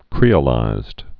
(krēə-līzd)